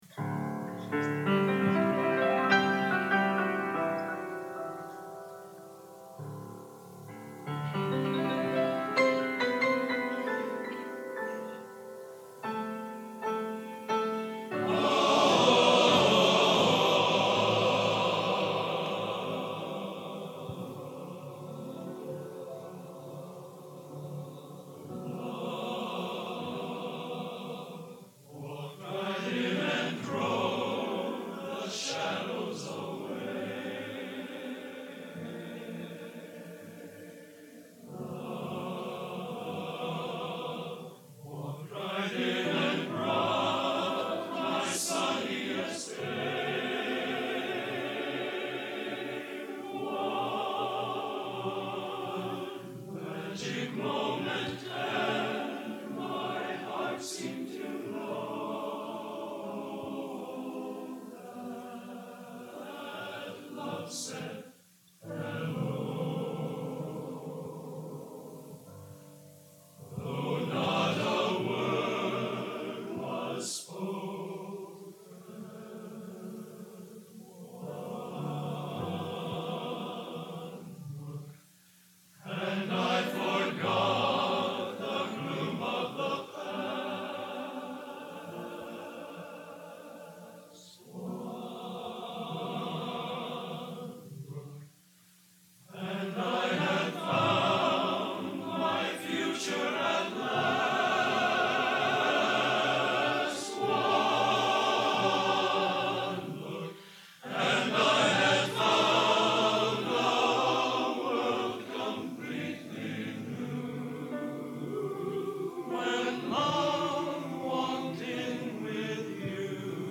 Genre: Popular / Standards Schmalz | Type: End of Season